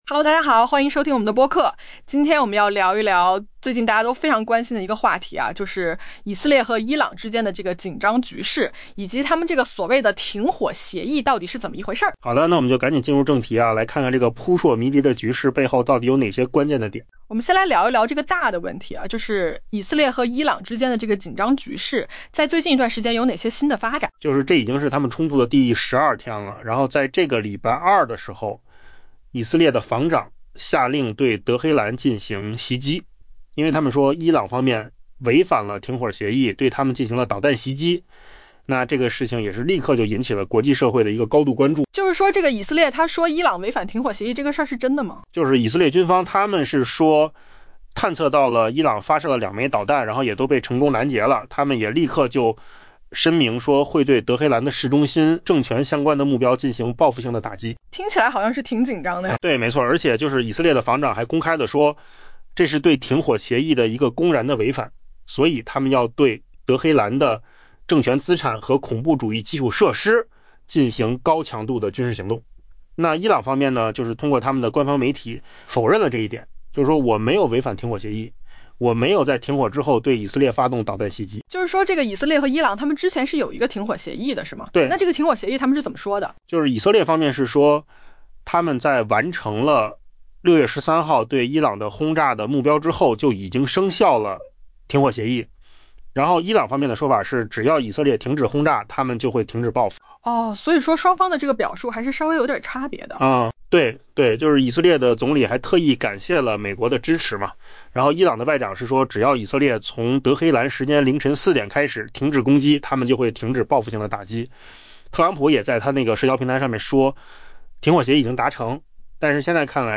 AI播客：换个方式听新闻（音频由扣子空间生成） 下载mp3